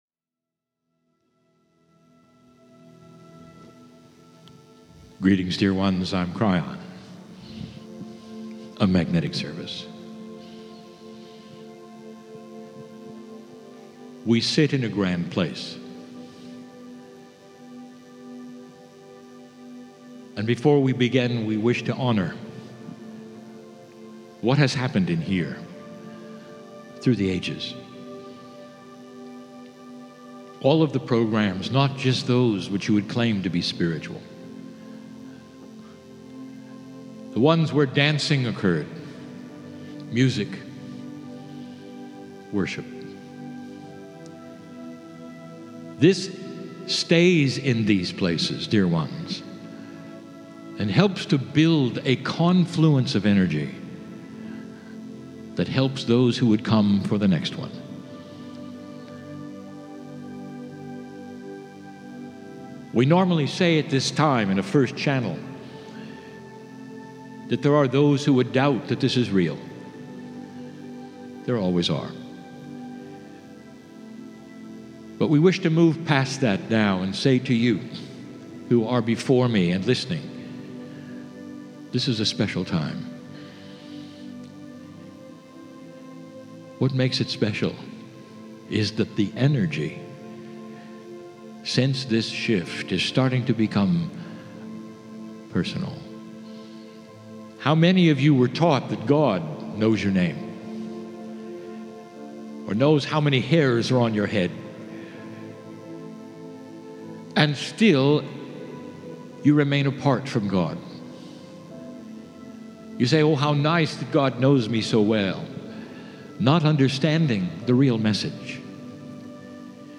Amsterdam - Holland - May 3, 2017
KRYON CHANNELLING AMSTERDAM CHANNELLING 1